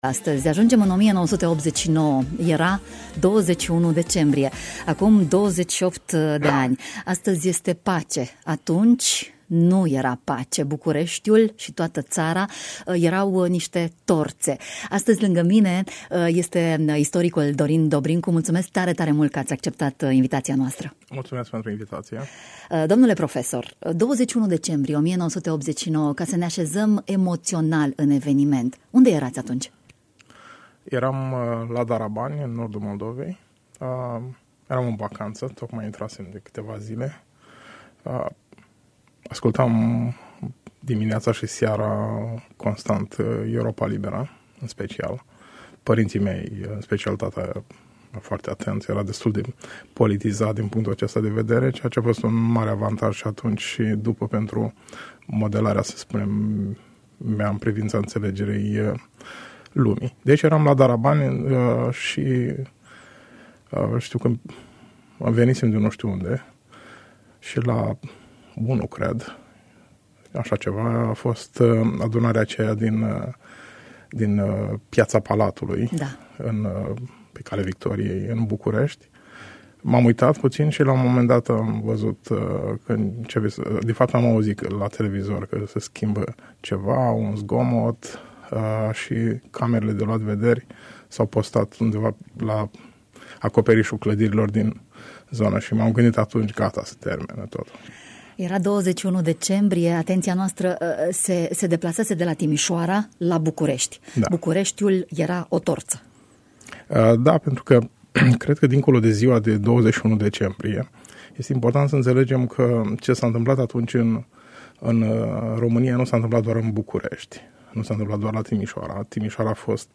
O emisiune